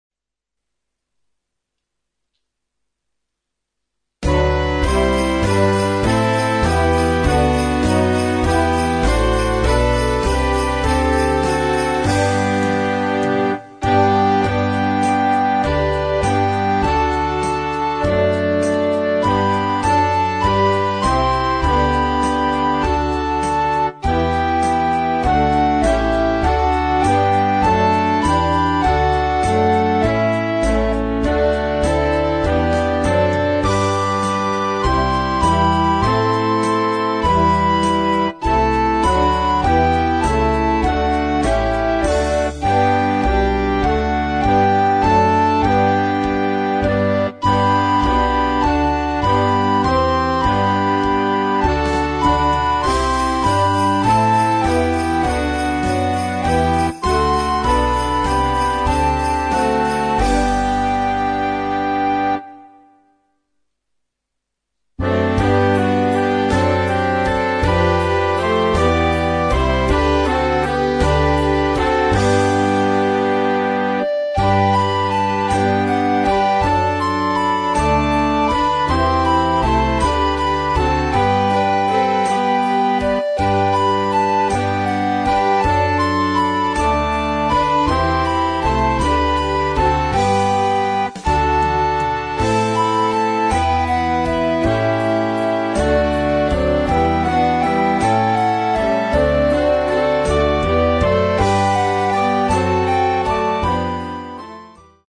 für Jugendblasorchester und Kinderchor (ad lib.)
3:10 Minuten Besetzung: Blasorchester PDF